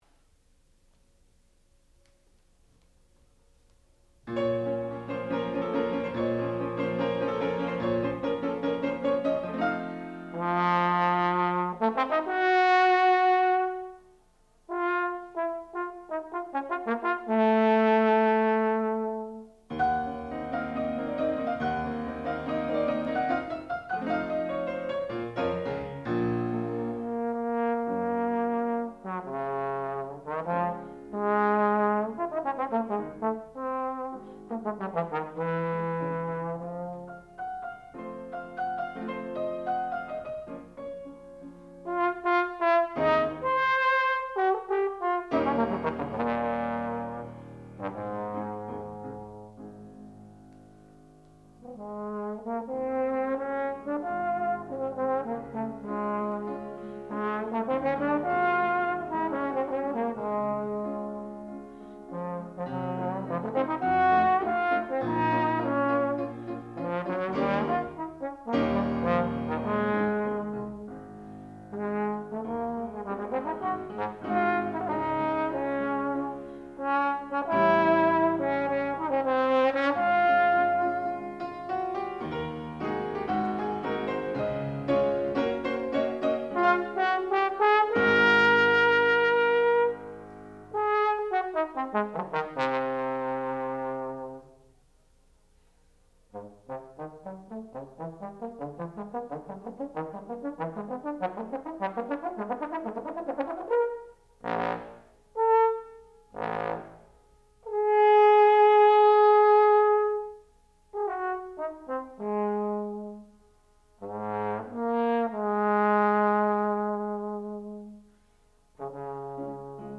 sound recording-musical
classical music
Advanced Recital
trombone